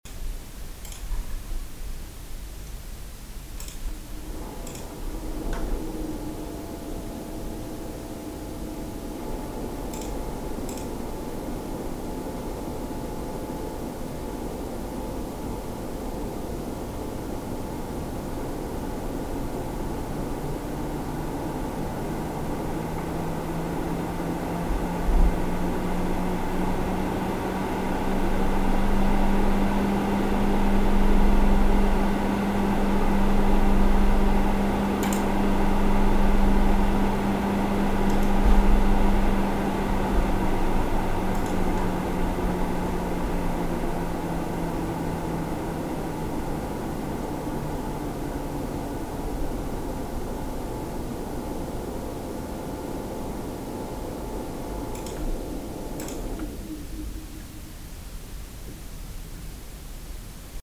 Unten ist die Aufnahme der Gainward Ghost Lüfter bei der Mindestdrehzahl mit einer alten CPU Lüfter Konfiguration unter CPU Last und am Hörplatz war die Gainward und auch die CPU Lüfterlautstärke bei den Geschwindigkeiten zu hören und das war insgesamt noch leise, aber nur weil die CPU Lüfter unter Last trotz "Schwebung" angenehm waren.
Aber Furmark GPU Last mit 200W TDP unterhalb der Lautstärke der originalen Gainward Lüfter bei 1300RPM, wo noch das leichte Rasseln der Spulen der Gainward auf dem Mikrofon hörbar ist, das sind hörbare Quantensprünge zu den Originallüftern.